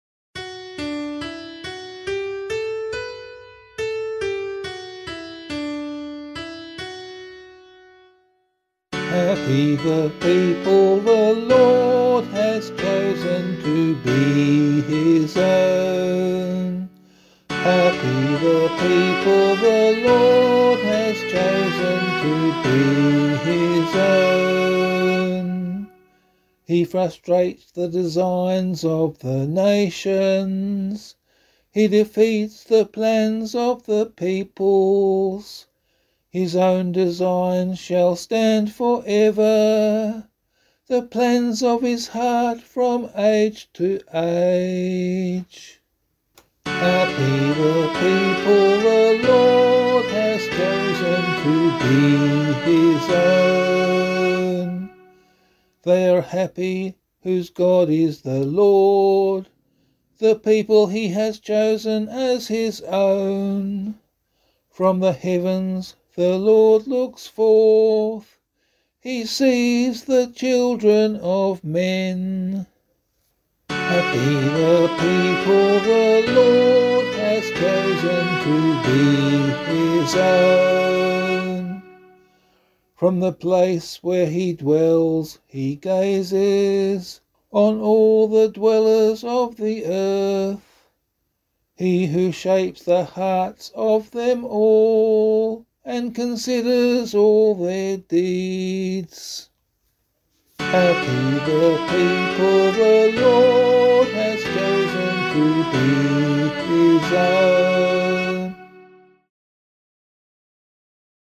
031 Pentecost Vigil Psalm 1 [LiturgyShare 5 - Oz] - vocal.mp3